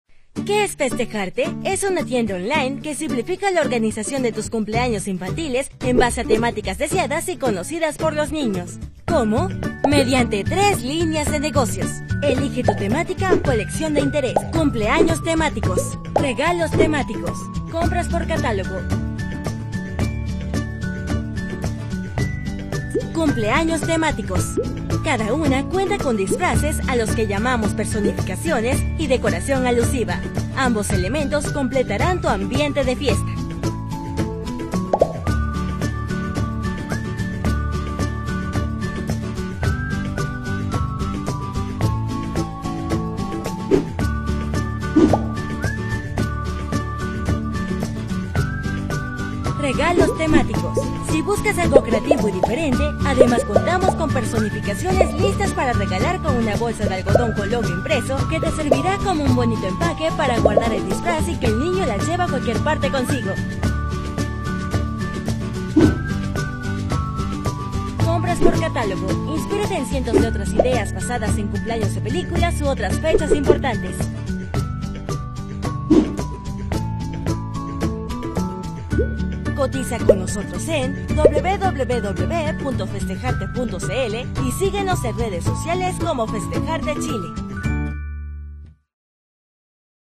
外籍西语男3-同伴女声